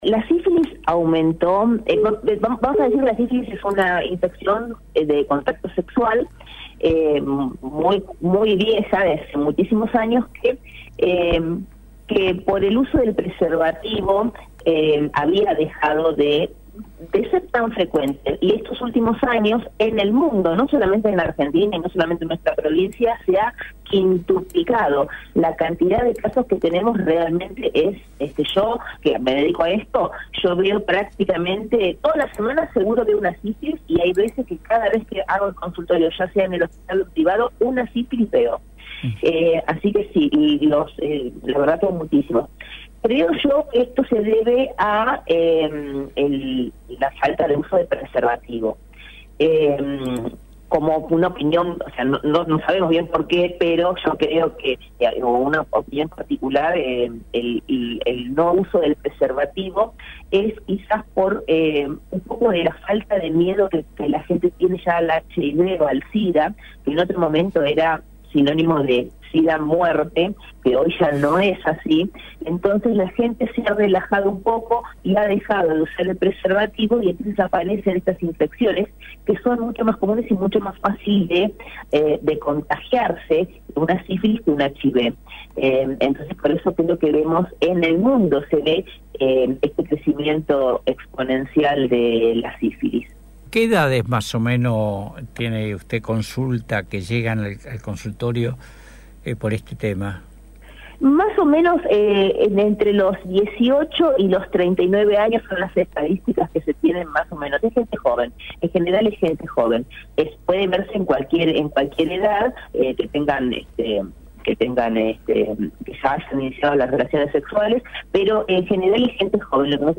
Este es el contenido de toda la entrevista